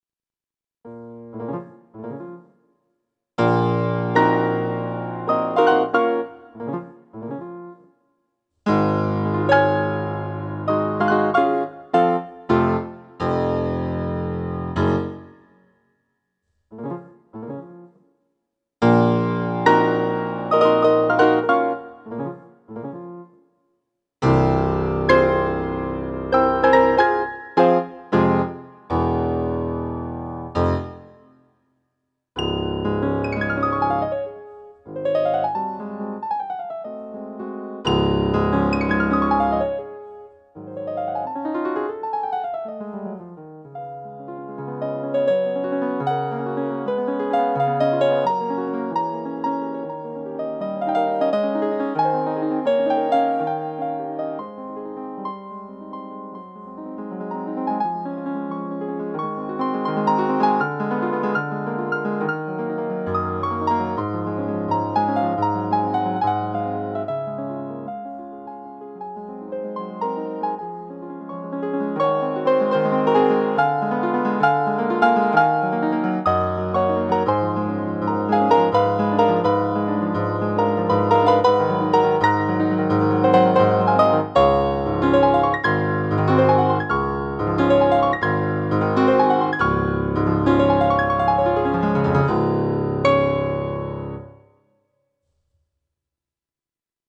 Acoustic piano (preset 8) - Chopin 2 Audio Inconnu
seven_demo_chopin_2.mp3